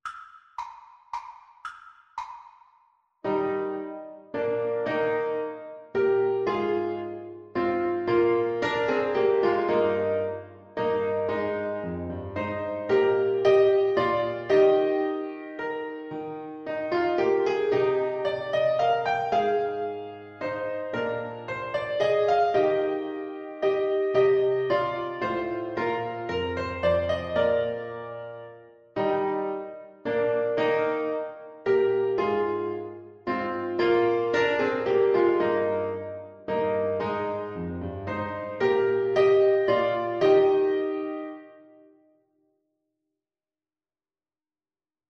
Play (or use space bar on your keyboard) Pause Music Playalong - Piano Accompaniment Playalong Band Accompaniment not yet available reset tempo print settings full screen
Eb major (Sounding Pitch) F major (Trumpet in Bb) (View more Eb major Music for Trumpet )
3/4 (View more 3/4 Music)
Moderately Fast ( = c. 112)